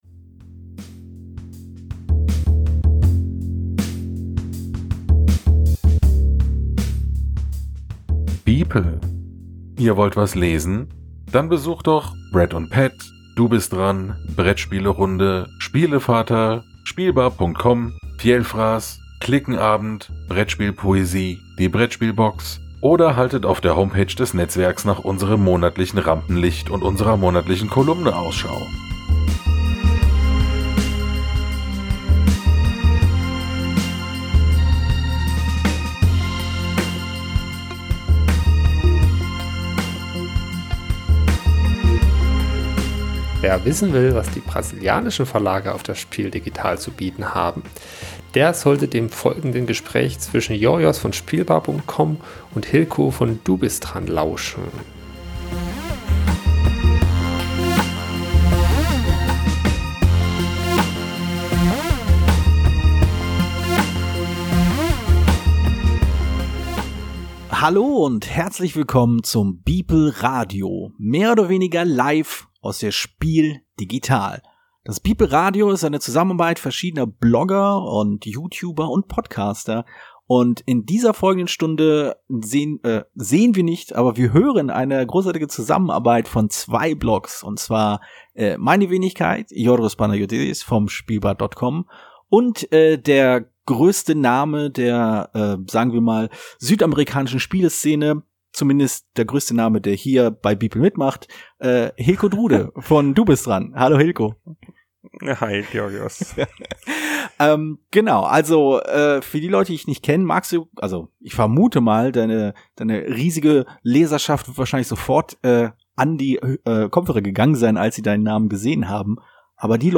Denn wir vom beeple Netzwerk bringen ein wenig Abwechslung in Euren Alltag ohne Spielen mit lieben Freunden. beeple Radio – beeple Talk im Livestream Am Sonntag, 5.